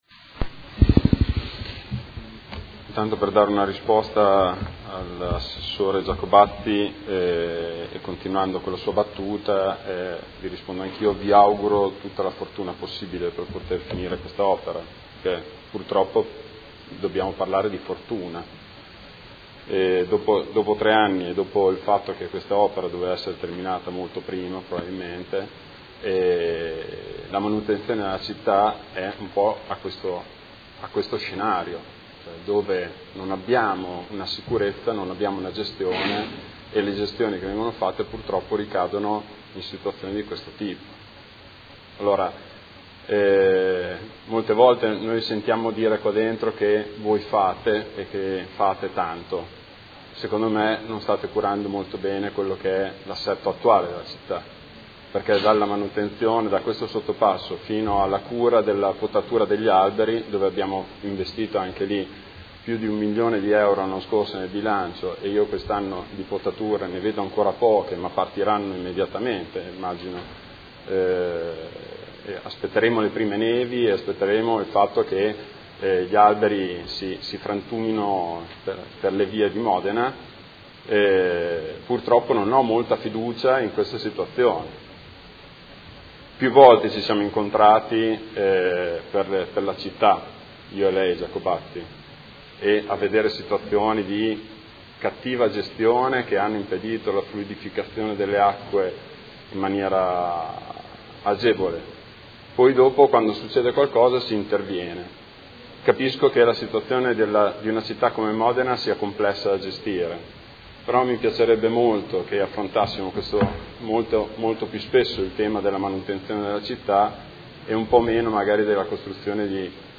Seduta del 30/11/2017. Dibattito su interrogazione del Gruppo M5S avente per oggetto: Completamento sottopasso ferroviario tra zona Crocetta (ex BenFra) e Via Scaglietti (ex Acciaierie) a fianco del cavalcavia Ciro Menotti